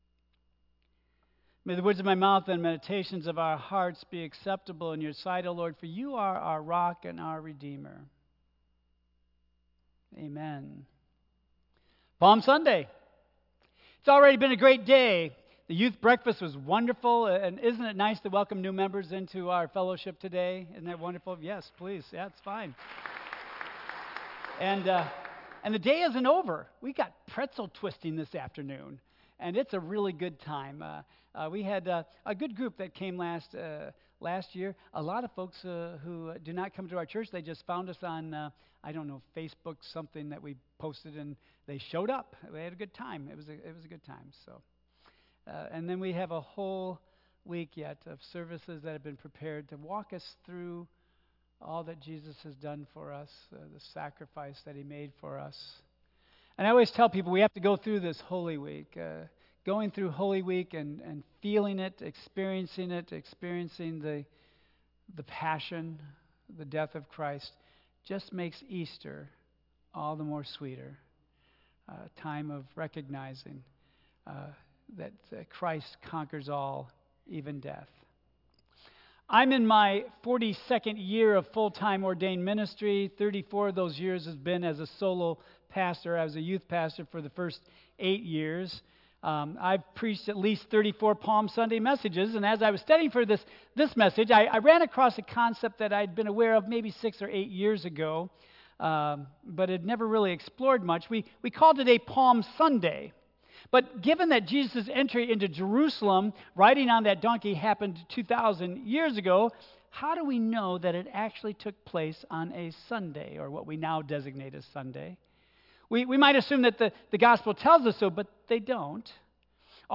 Tagged with Central United Methodist Church , Lent , Michigan , Sermon , Waterford , Worship Audio (MP3) 7 MB Previous The Clash of an Empire and Kingdom